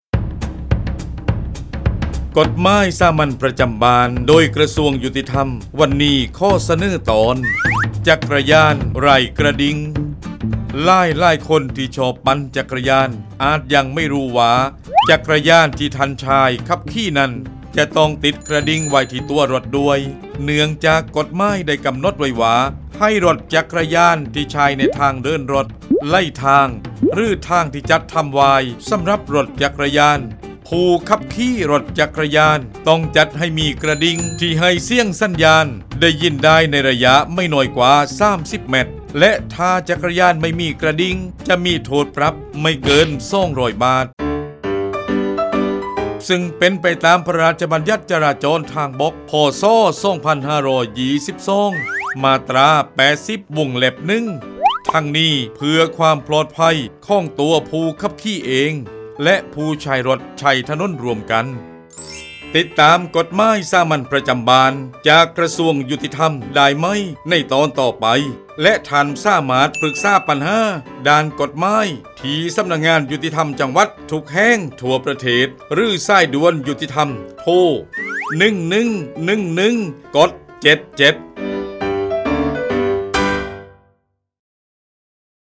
กฎหมายสามัญประจำบ้าน ฉบับภาษาท้องถิ่น ภาคใต้ ตอนจักรยานไร้กระดิ่ง
ลักษณะของสื่อ :   คลิปเสียง, บรรยาย